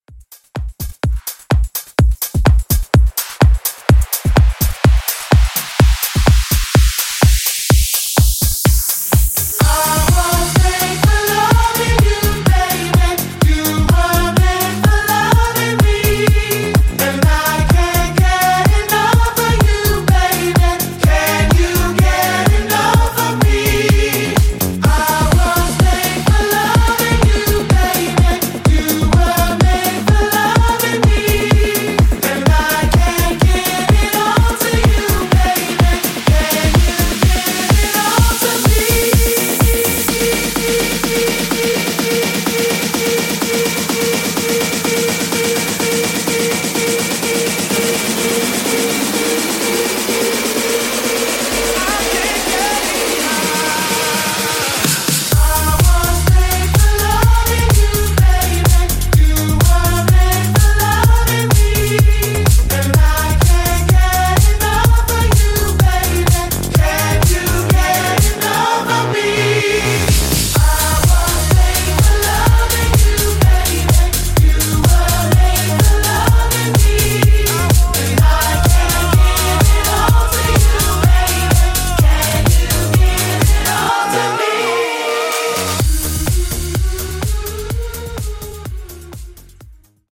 Extended House)Date Added